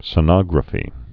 (sə-nŏgrə-fē)